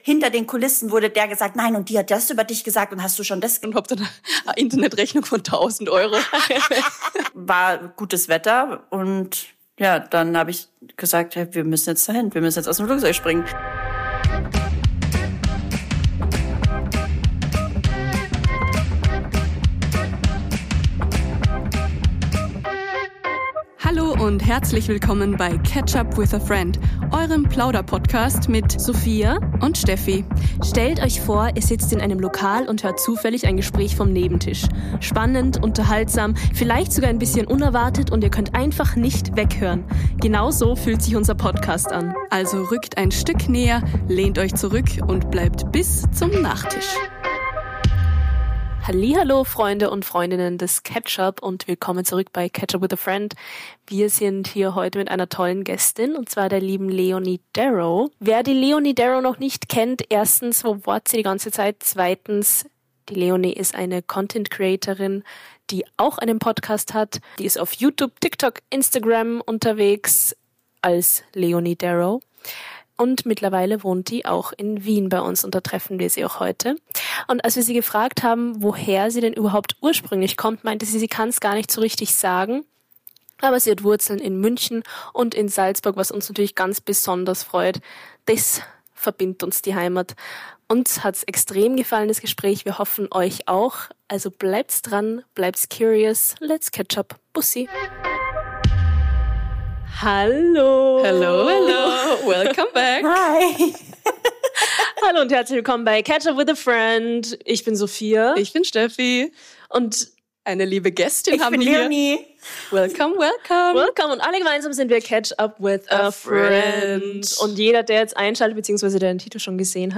In dieser Folge freuen wir uns über die allererste Lady bei uns zu Gast! Euch erwartet ein Gespräch über Höhen, Tiefen und freie Fälle - wortwörtlich.